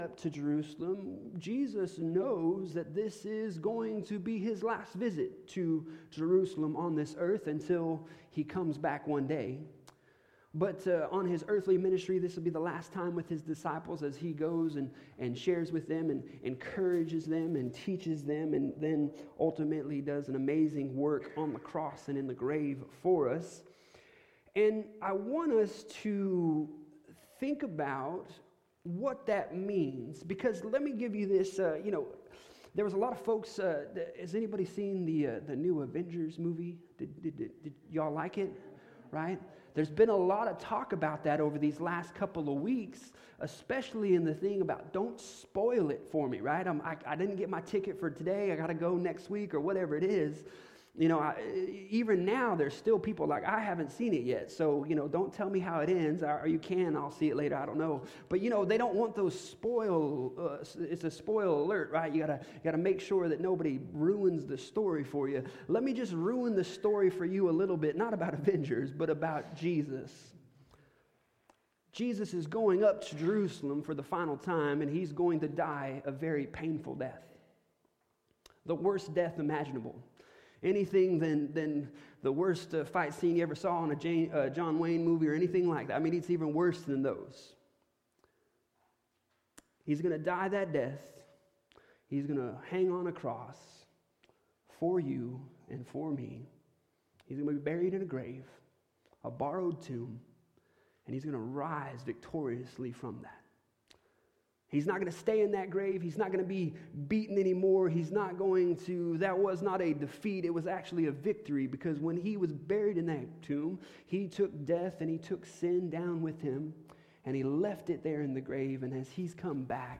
Passage: Matthew 21:1-11 Service Type: Sunday Morning